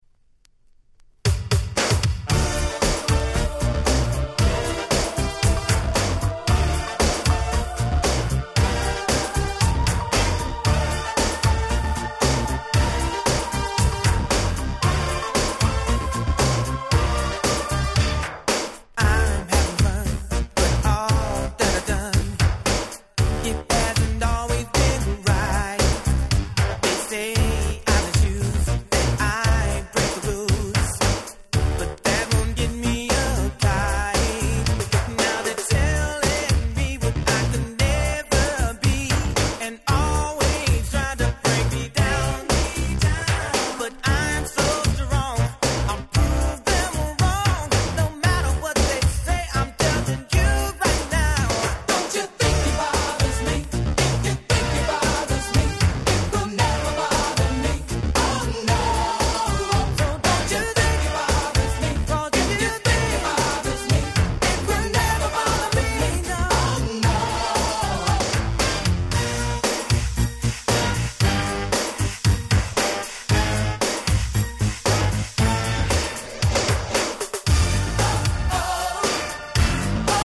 US Original Press.
89' Big Hit R&B LP !!